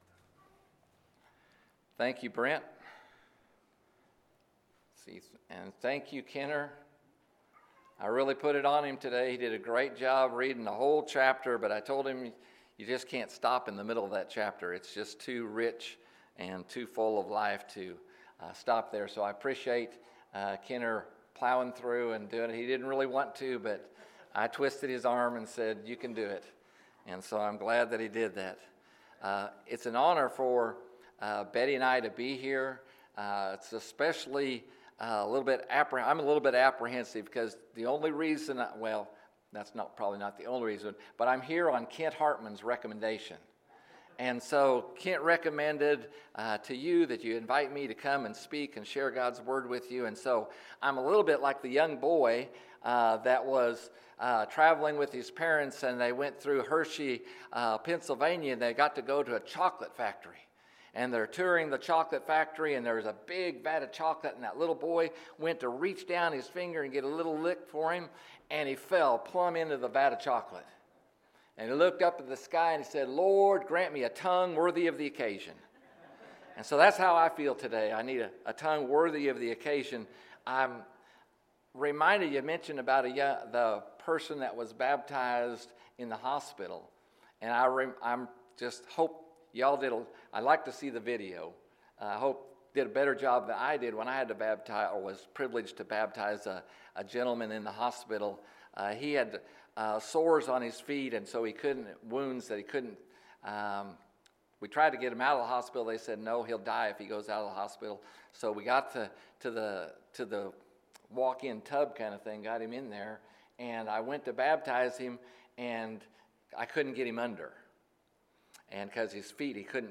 Confident Living – Sermon